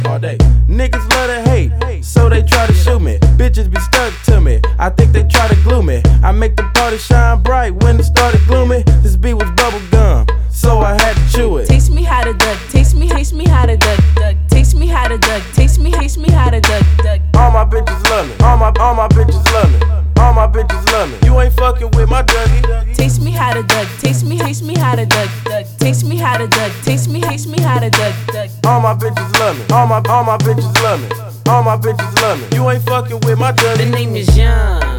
Hip-Hop Rap Rap West Coast Rap Dance
Жанр: Хип-Хоп / Рэп / Танцевальные